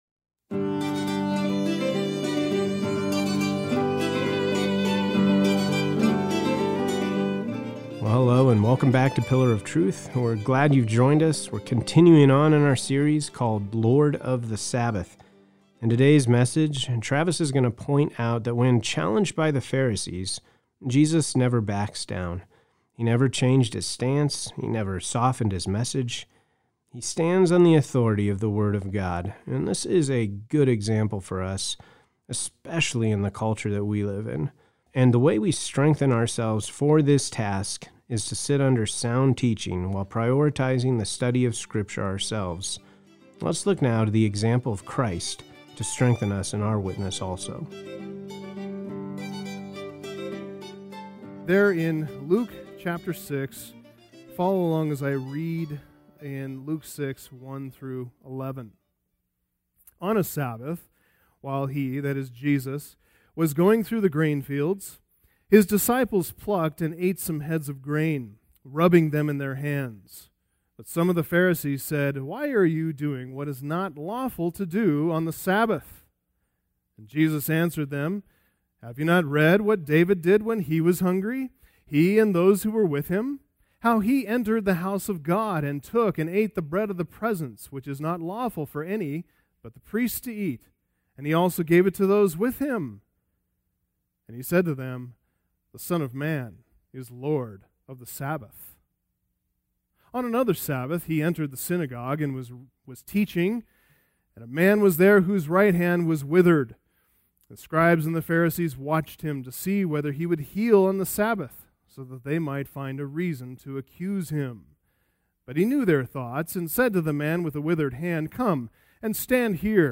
Message Transcript